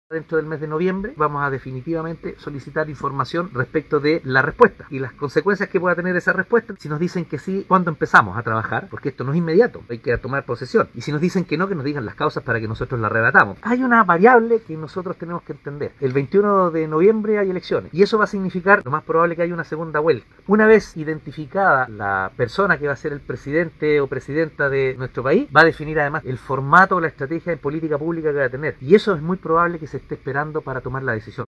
02-ALCALDE-Noviembre-y-las-elecciones.mp3